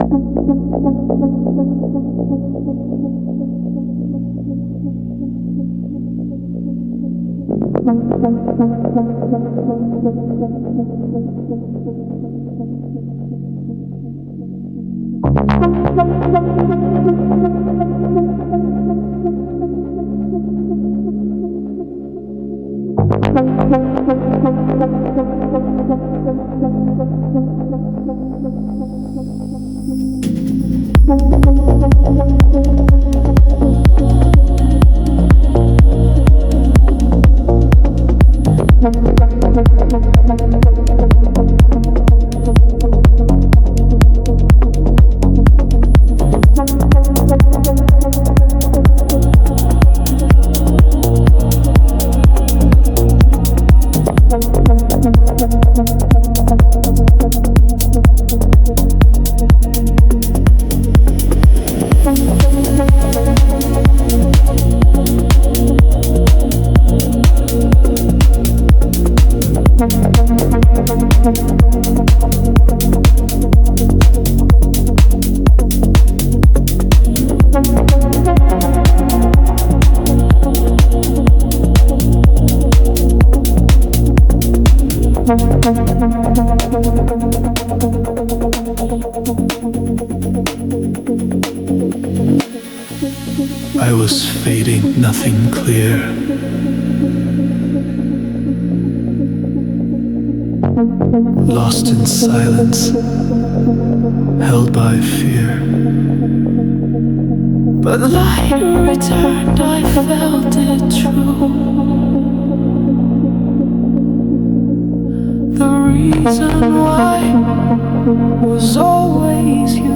Жанр: Melodic House